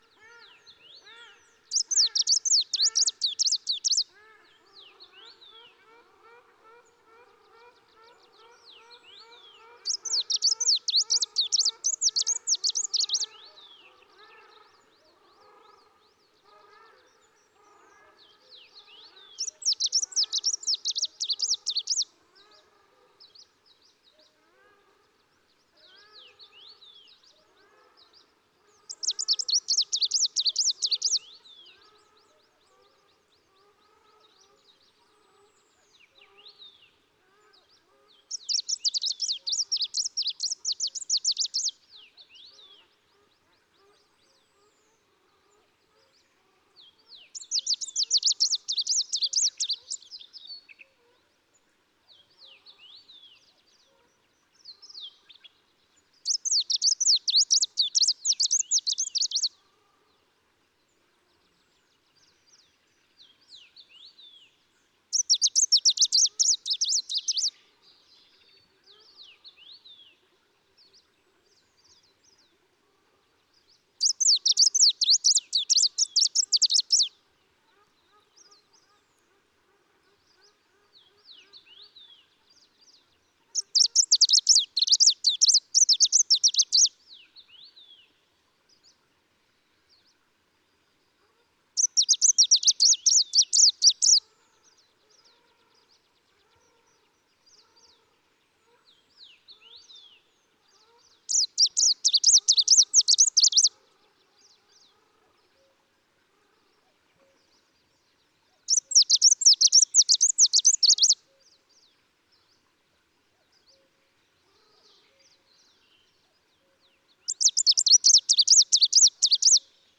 PFR09385, 2-10, 130605, Two-barred Warbler Phylloscopus plumbeitarsus, song,
Terkiin Tsagaan Nuur, Mongolia